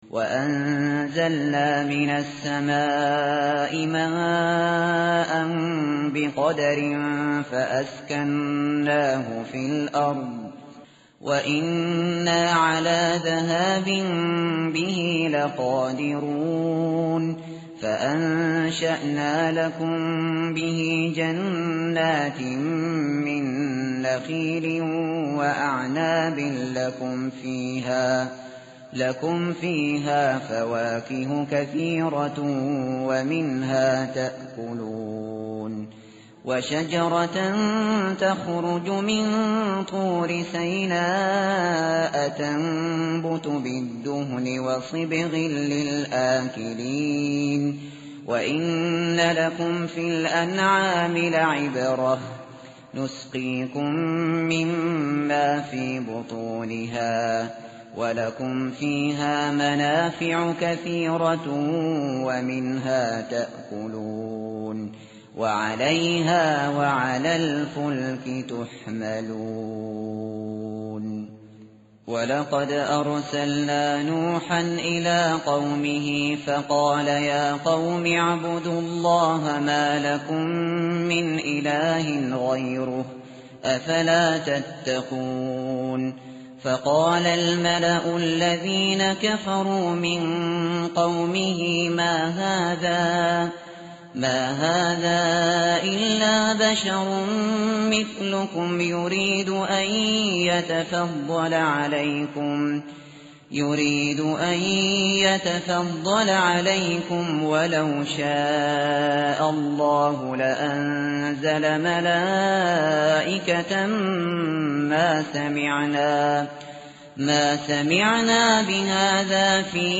tartil_shateri_page_343.mp3